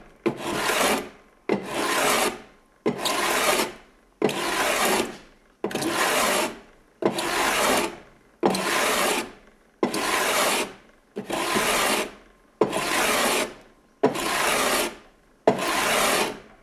Cepillo de madera
Sonidos: Industria
Madera y mueble: Herramientas manuales